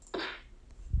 Punch (1)